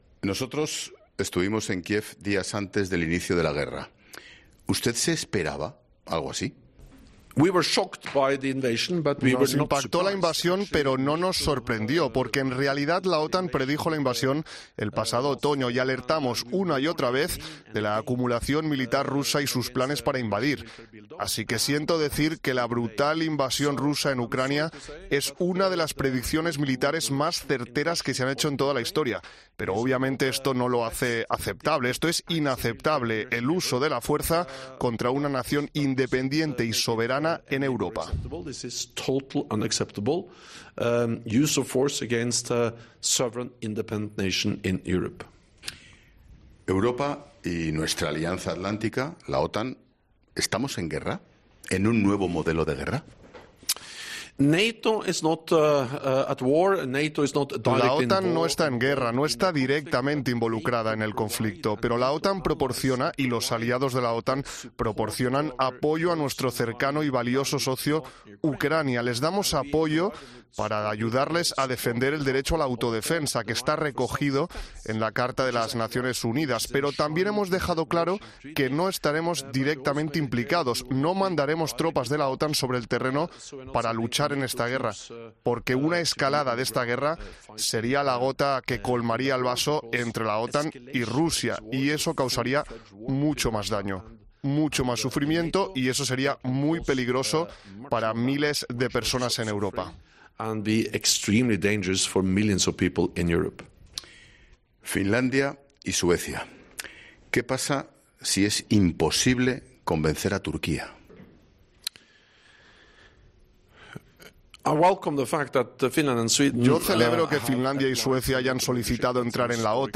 A la "importancia" de la cumbre del 29 y 30 de junio también se ha referido el Secretario General de la Alianza en la entrevista que ha concedido a 'La Linterna' de COPE, en una de las entrevistas exclusivas realizadas en esta visita a España (la única a una radio).